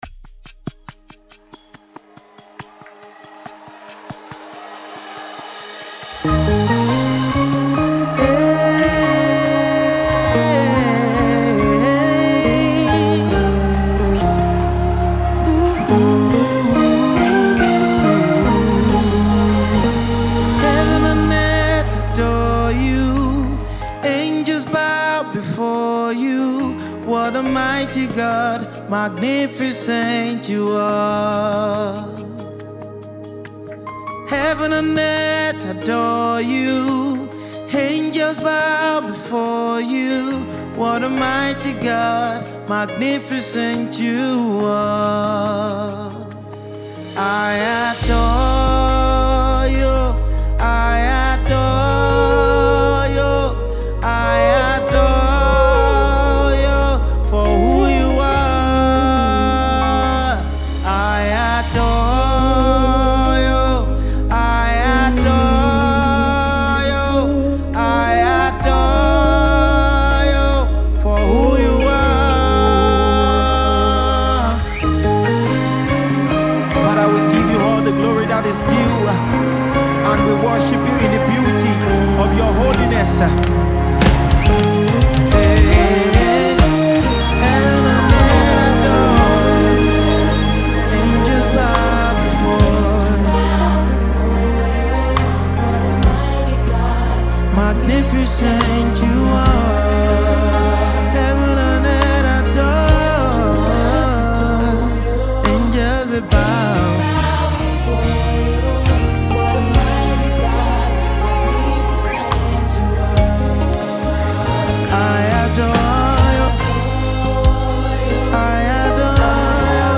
powerful vertical worship anthem